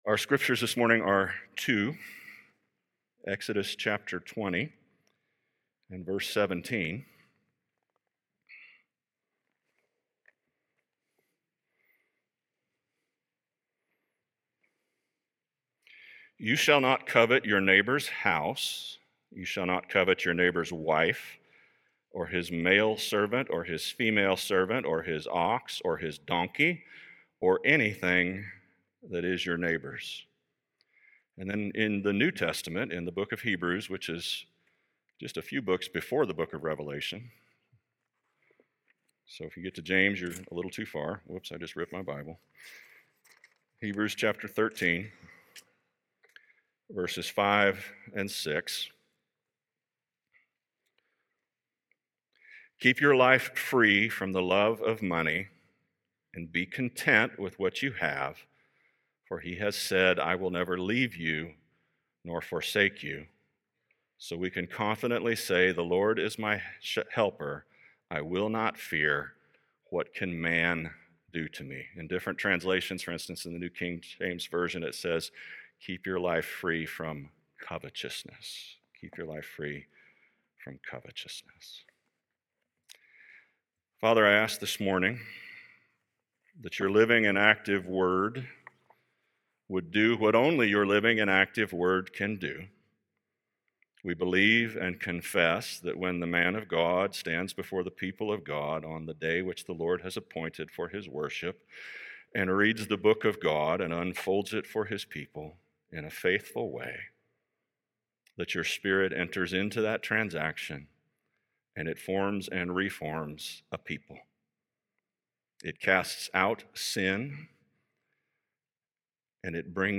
Study Helps for Sermons - First Reformed Church